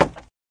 woodwood2.ogg